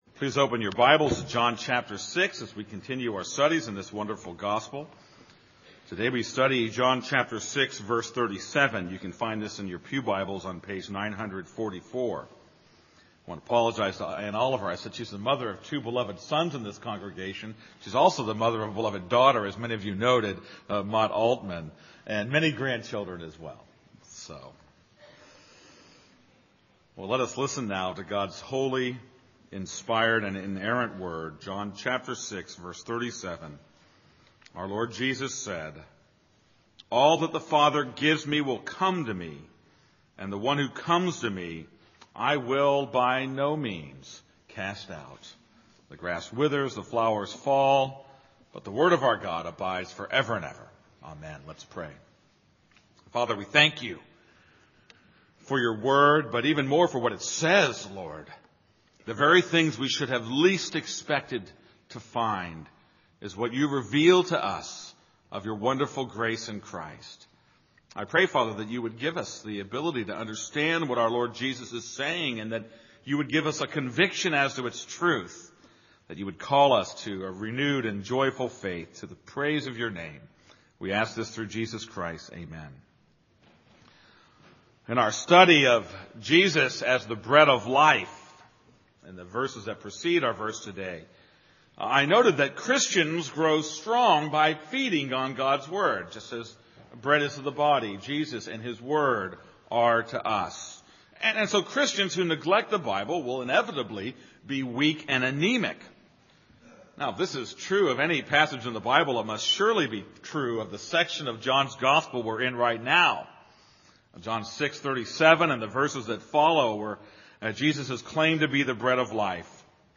This is a sermon on John 6:37.